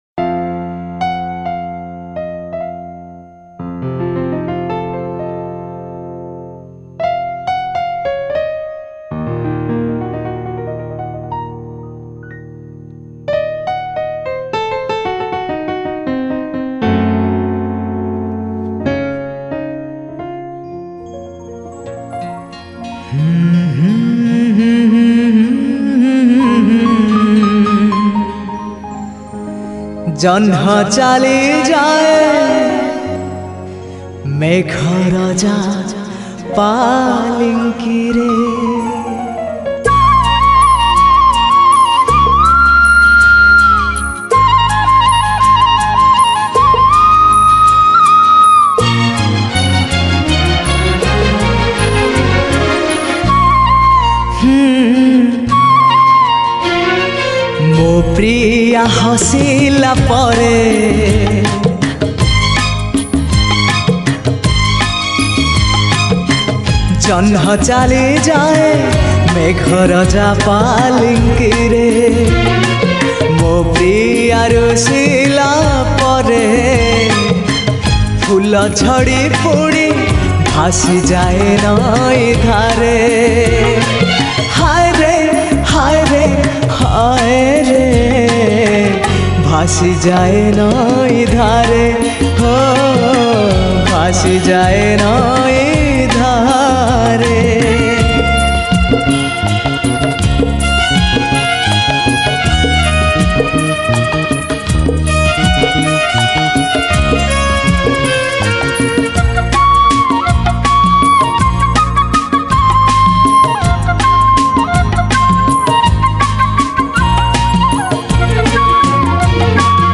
Odia Romantic Song